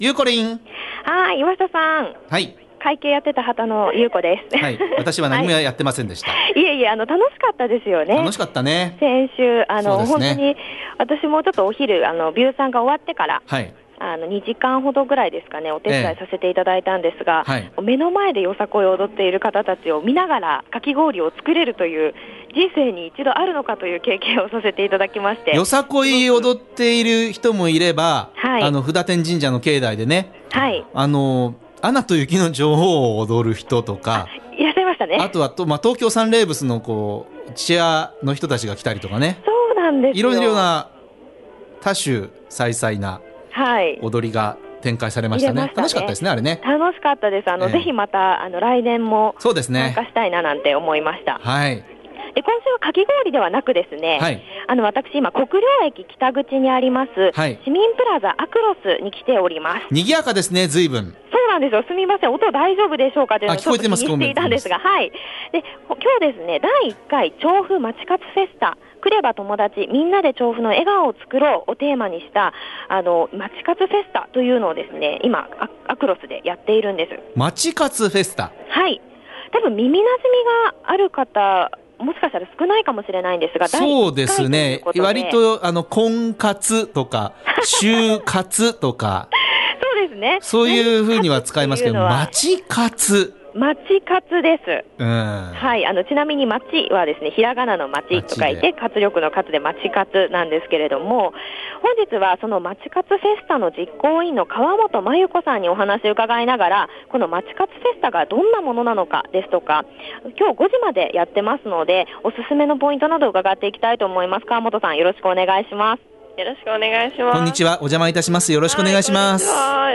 ９月最初のびゅーサン街角レポートは、「第1回まち活フェスタ」（国領駅あくろす）にお邪魔してきました☆